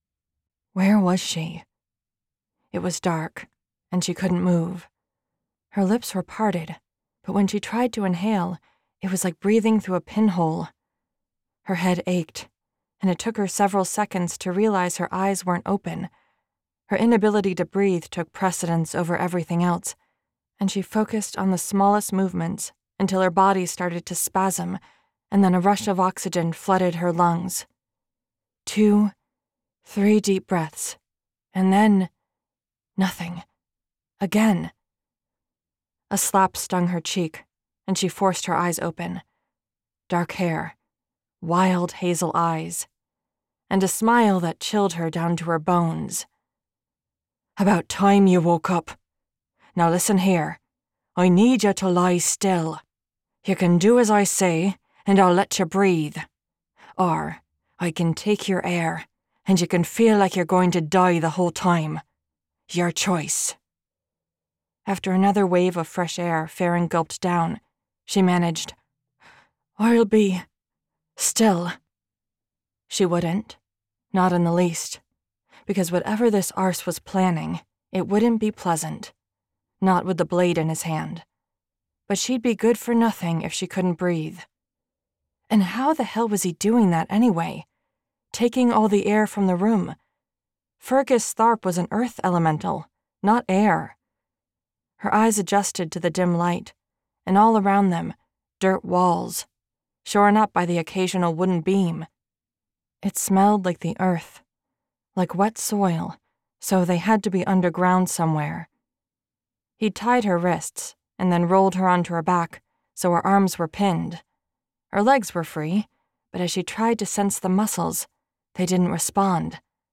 You can now buy the audiobook of A Shift in the Earth directly from me!